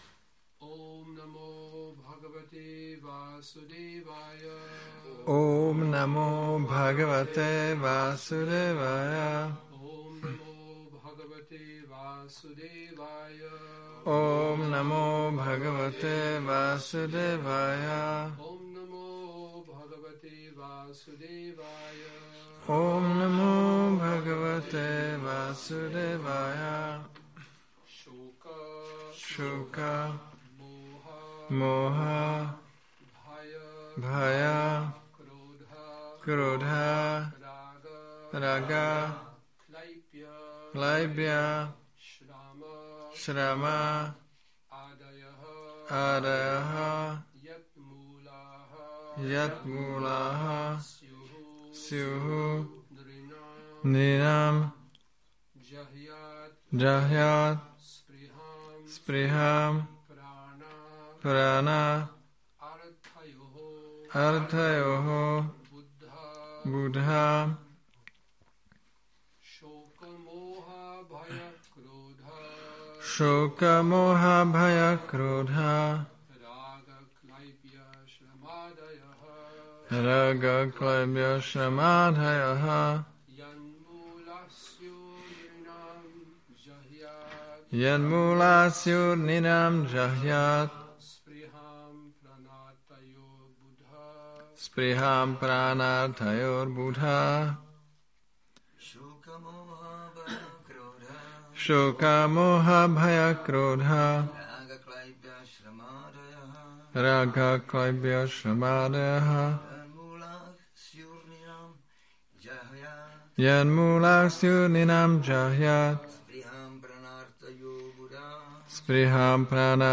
Přednáška SB-7.13.34 Šríla Bhaktisiddhánta Sarasvatí Thákura appearance day